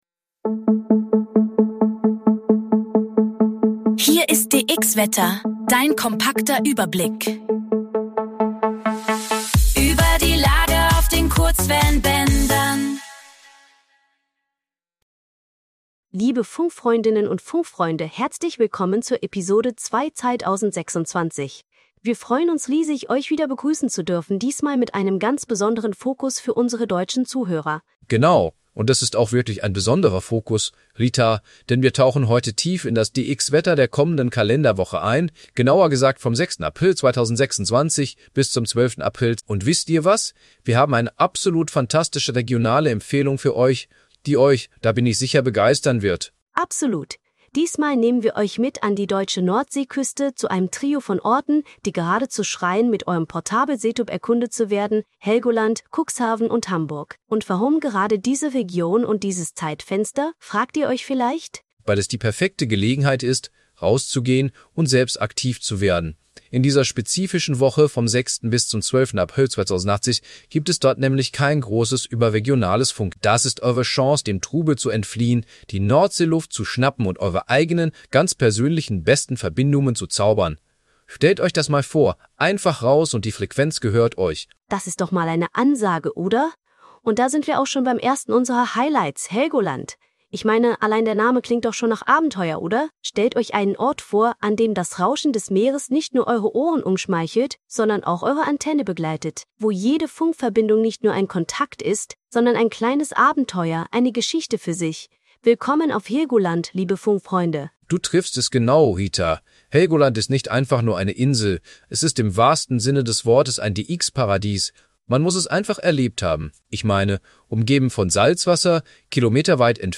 (Aktuell ist es ein Versuch, ALLES durch KI generieren zu lassen) Mehr